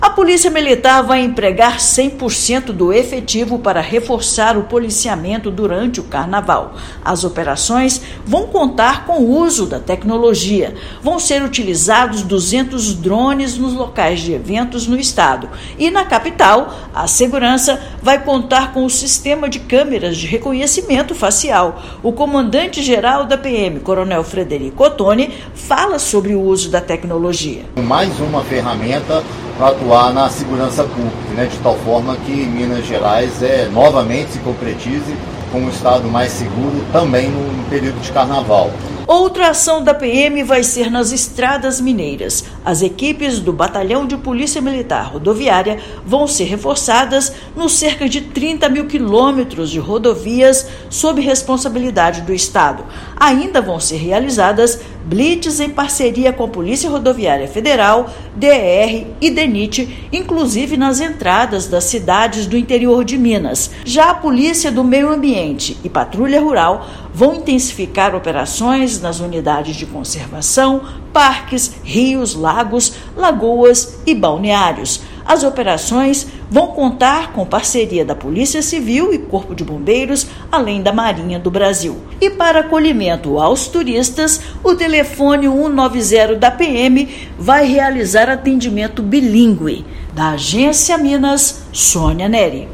Operações serão reforçadas nas áreas urbanas, rurais, regiões de lagos e balneários, além das estradas. Ouça matéria de rádio.